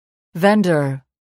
단어번호.0685 대단원 : 3 소단원 : a Chapter : 03a 직업과 사회(Work and Society)-Professions(직업) vendor [véndər] 명) 행상인, 판매 회사, 매도인 mp3 파일 다운로드 (플레이어바 오른쪽 아이콘( ) 클릭하세요.)
vendor.mp3